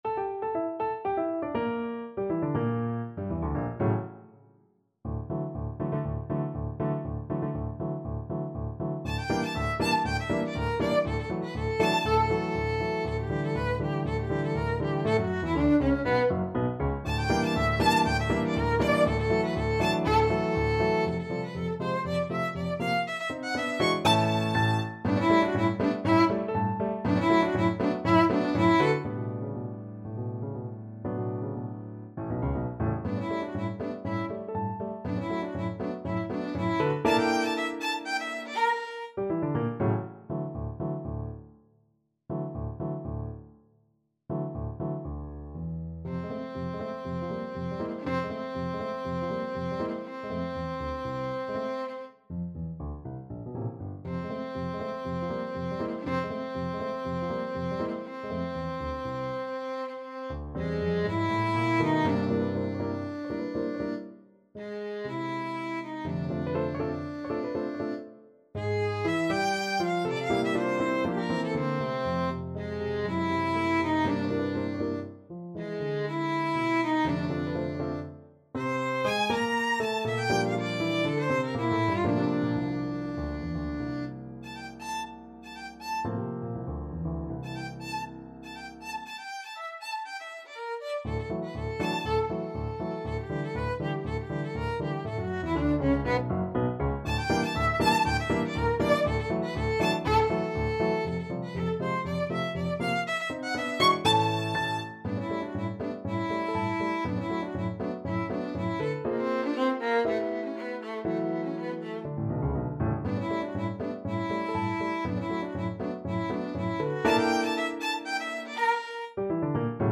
ViolinPiano
2/4 (View more 2/4 Music)
Allegro giusto (View more music marked Allegro)
Classical (View more Classical Violin Music)